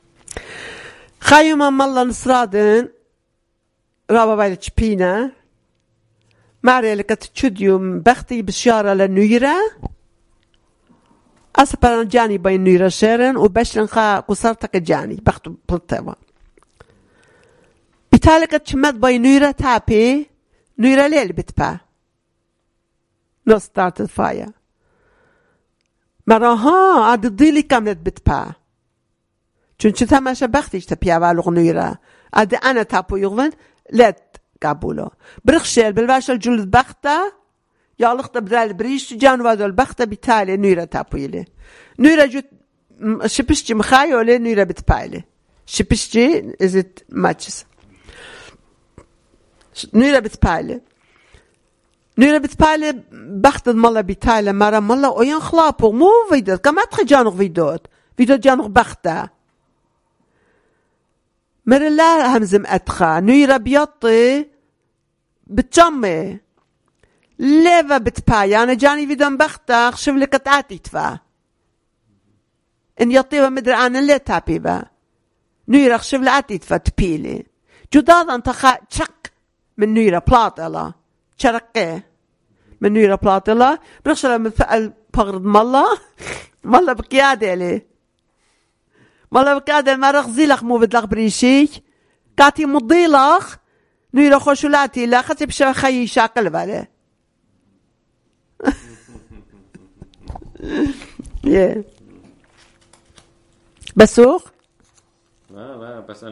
Urmi, Christian: Problems Lighting a Fire